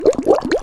Sfx Boat Through Water Sound Effect
sfx-boat-through-water-3.mp3